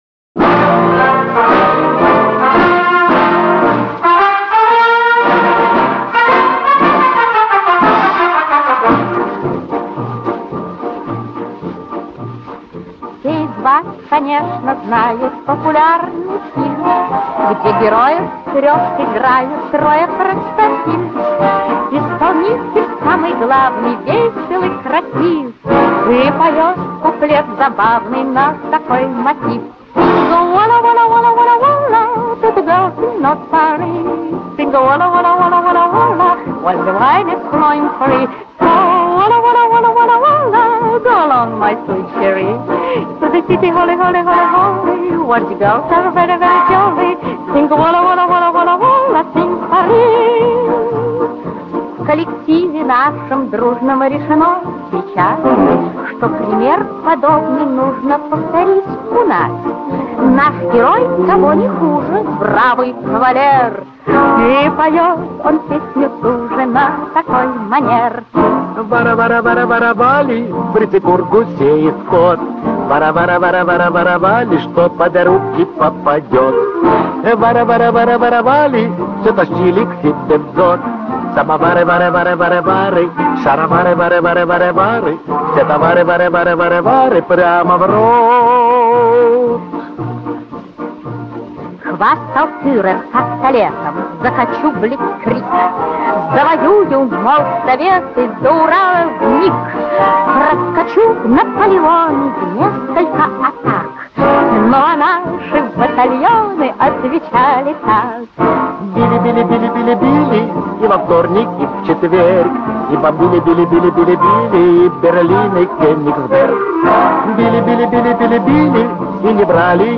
Куплеты